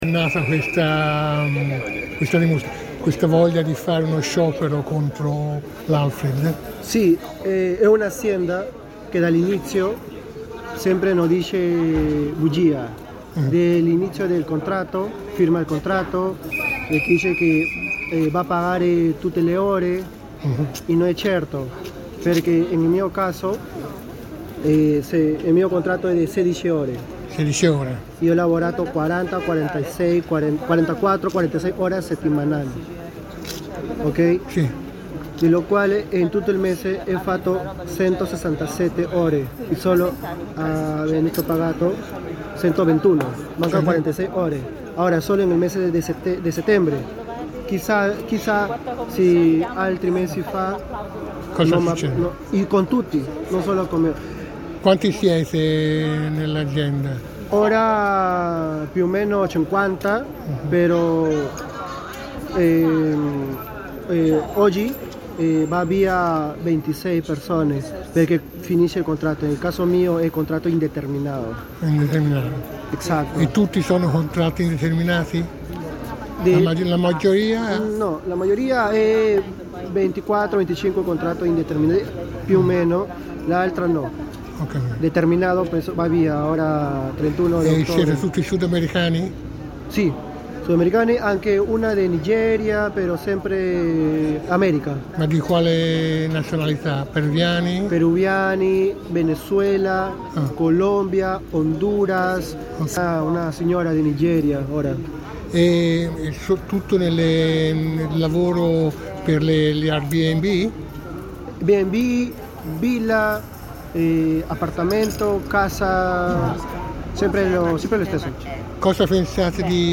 Stamani davanti alla sede della azienda Alfred nel centro storico di Firenze si sono radunati in un presidio  assisito dalla Cgil Filcams Firenze un nutrito gruppo di lavoratori per denunciare  la scorretta applicazione dei contratti di lavoro  dell’azienda e soprattutto per  condizioni di lavoro dignitose e non offensive.
Questo il racconto di un lavoratore in sciopero: